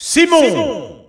Announcer pronouncing Simon's name in French.
Category:Simon (SSBU) Category:Announcer calls (SSBU) You cannot overwrite this file.
Simon_French_Announcer_SSBU.wav